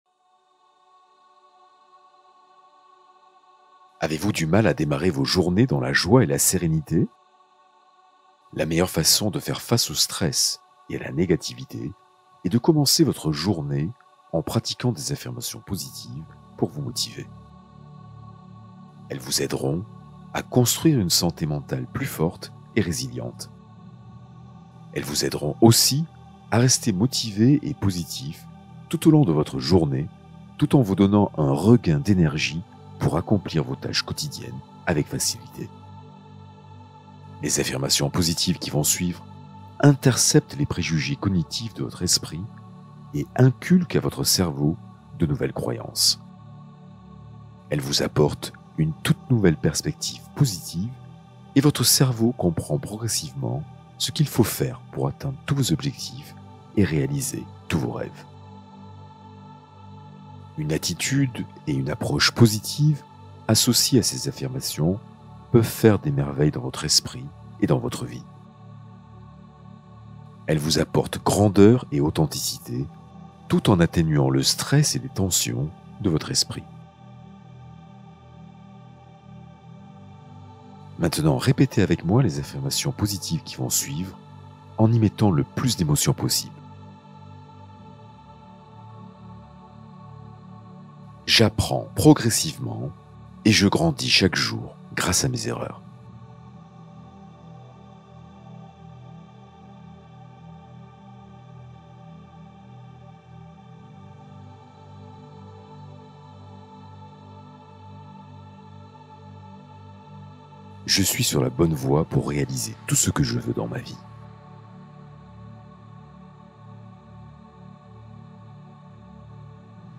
Hypnose d’amélioration personnelle pour renforcer ton pouvoir intérieur